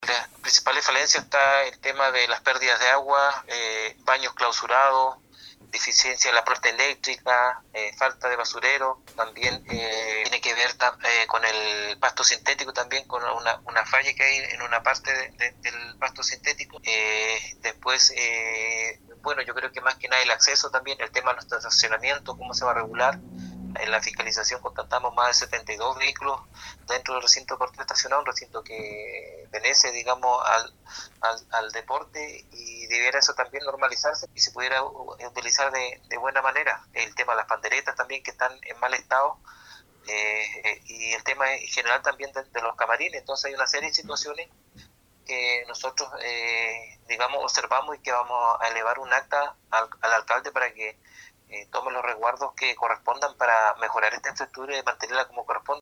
10-CONCEJAL-ALEX-MUÑOZ-2.mp3